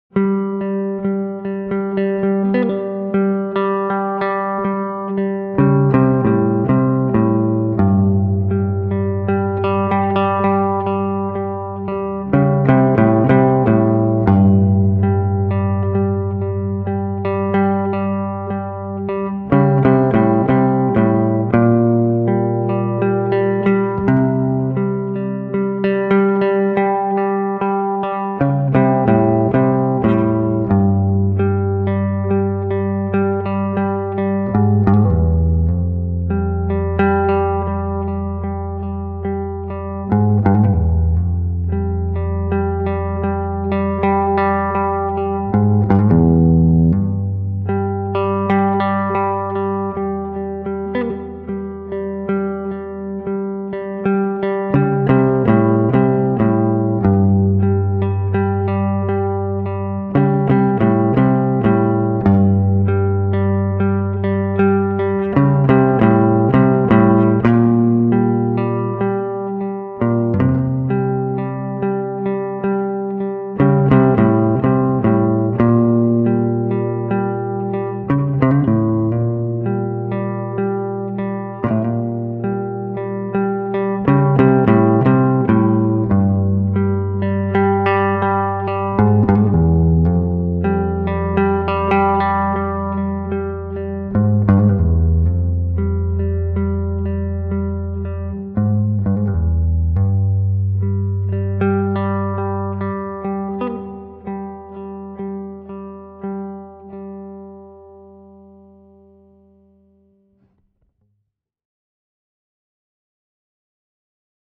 a solo electric guitar improv
guitar solo